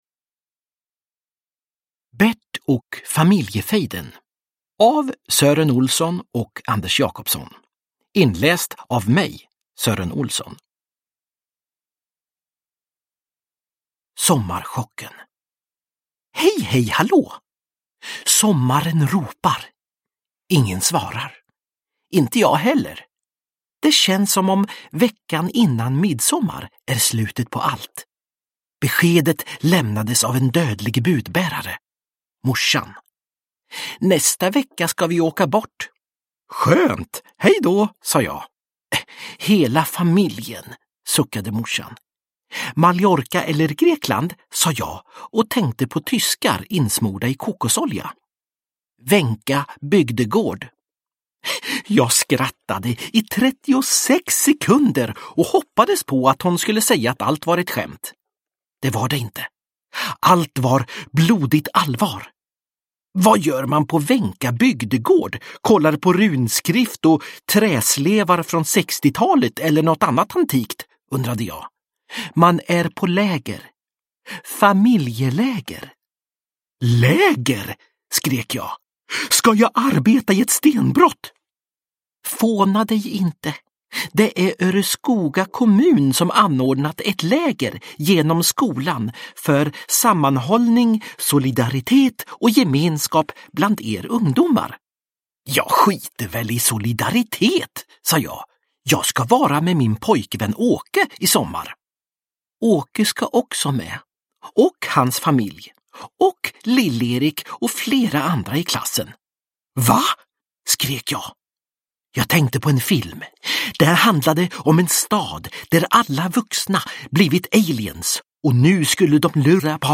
Bert och familjefejden – Ljudbok – Laddas ner
Uppläsare: Sören Olsson